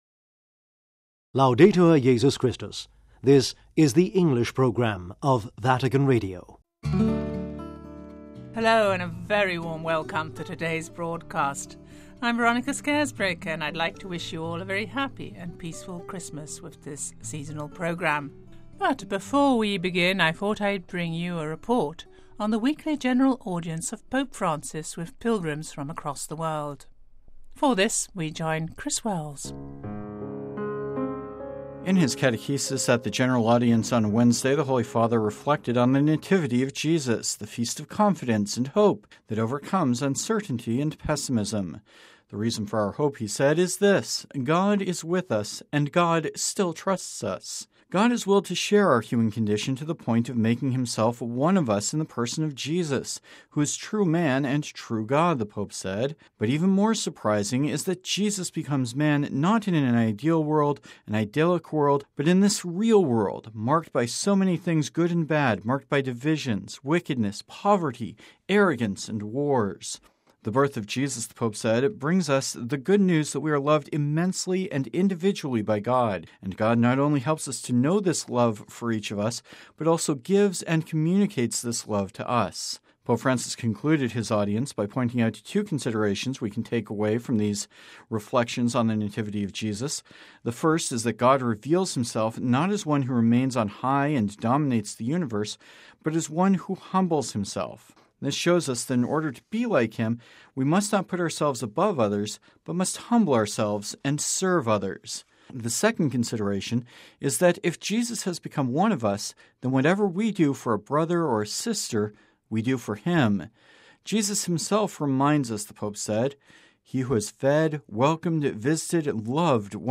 The Pope and the People - We bring you a report on the weekly general audience of Pope Francis with pilgrims from across the world.